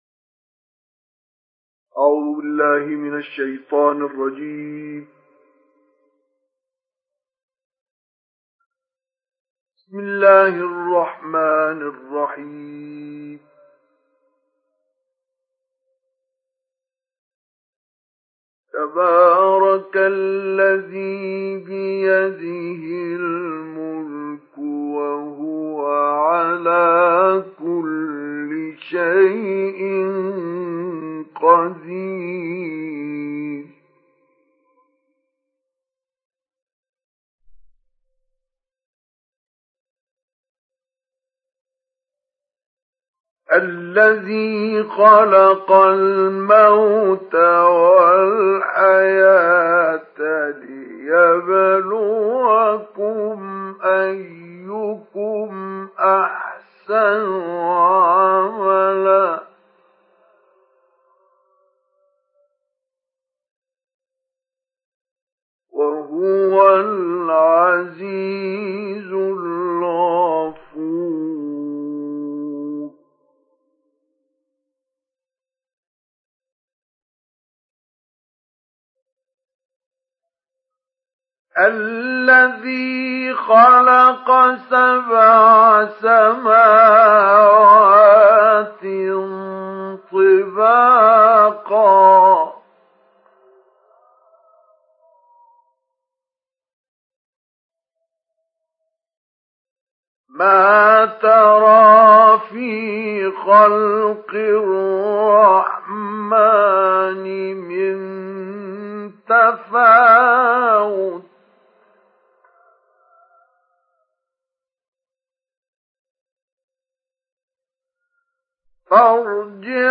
سُورَةُ المُلۡكِ بصوت الشيخ مصطفى اسماعيل